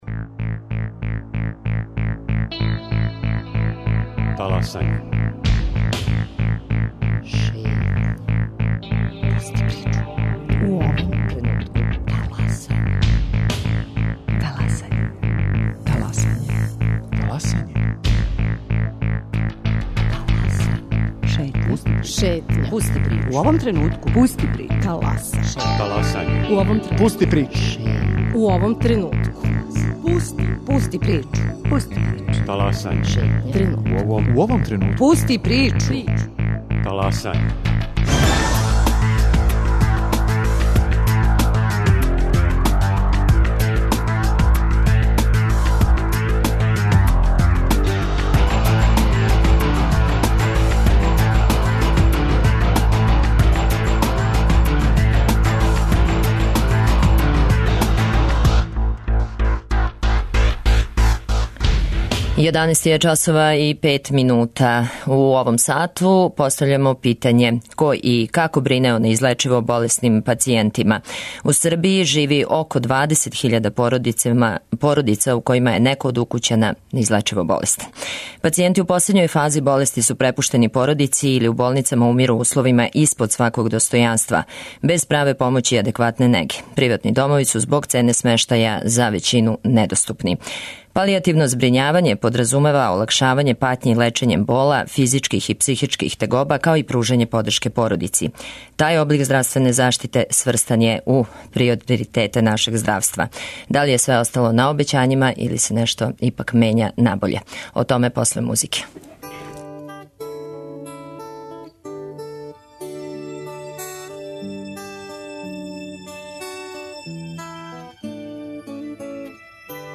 Гости: представници министарства здравља, лекари и чланови хуманитарне организације "Белхоспис".